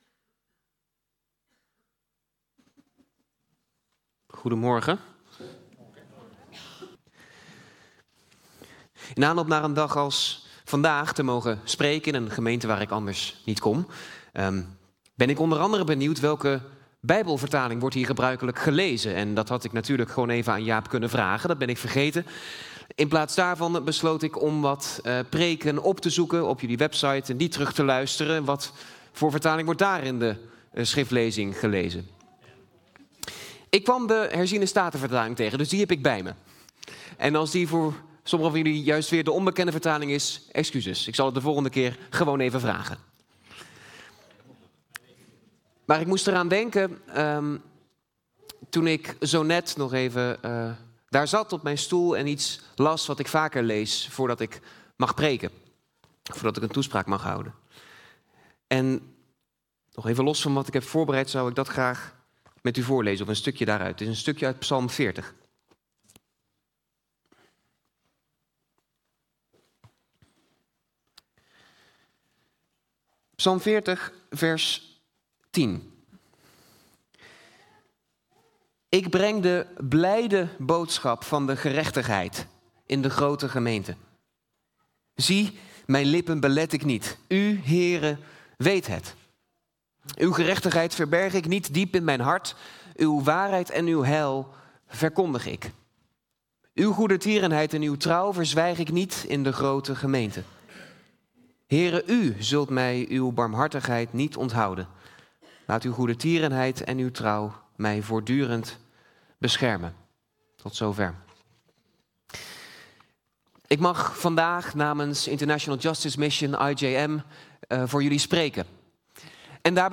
Toespraak 14 juli: Roeping